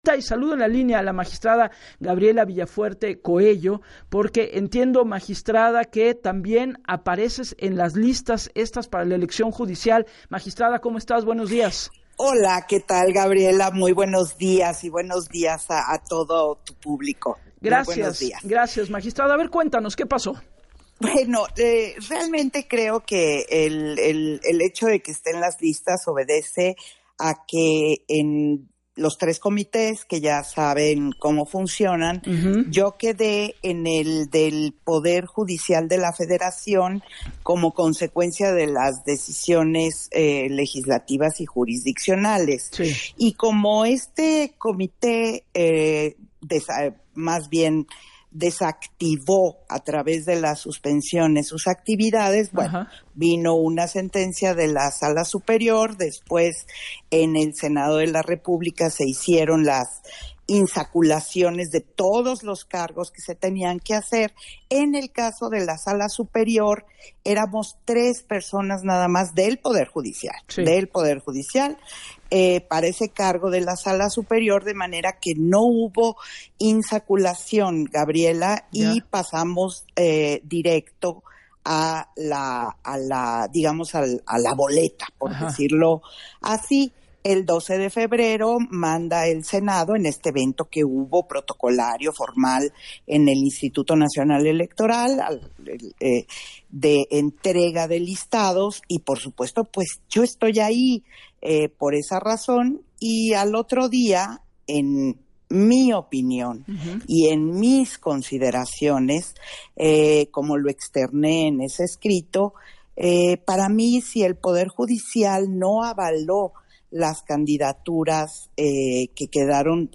En entrevista para “Así las Cosas” con Gabriela Warkentin, detalló su presencia en las listas del Comité del Poder Judicial de la Federación fue “consecuencia de las decisiones legislativas y jurisdiccionales, y como este Comité desactivó a través de las suspensiones sus actividades. En el Senado de la República se hizo la insaculación y en el caso de la Sala Superior, pasamos directo los tres postulantes a la boleta”.